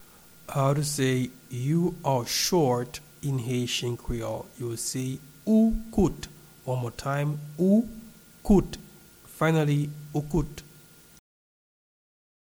You-are-short-in-Haitian-Creole-Ou-kout-pronunciation.mp3